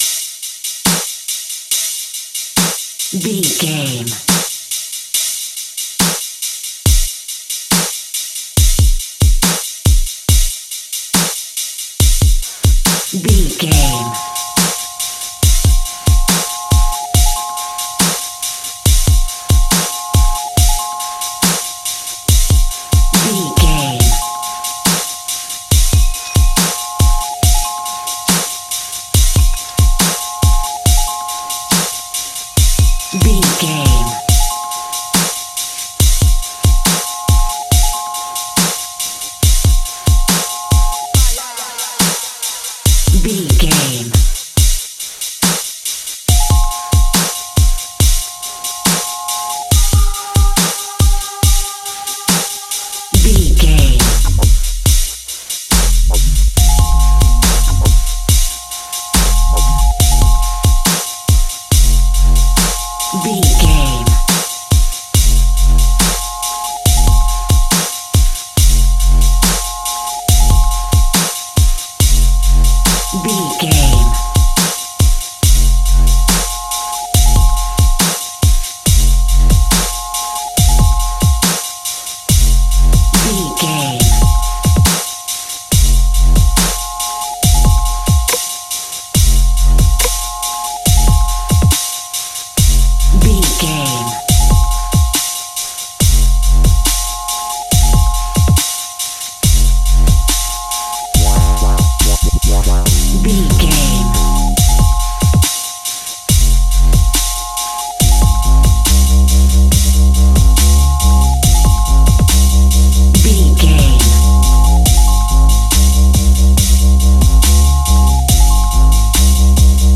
Thriller
Ionian/Major
Fast
eerie
intense
ominous
haunting
energetic
bouncy